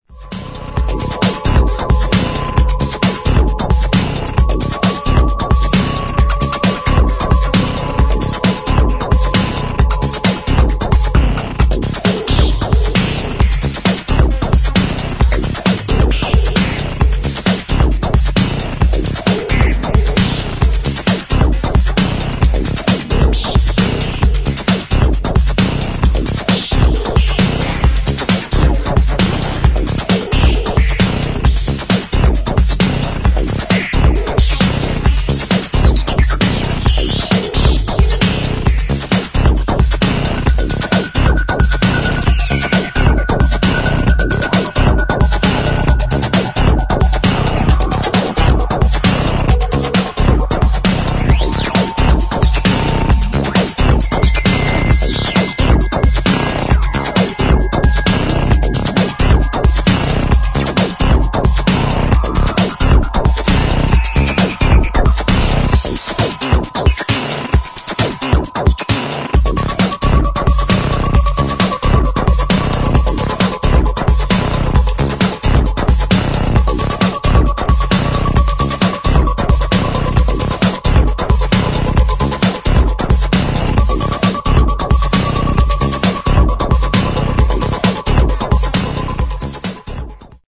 [ ELECTRO ]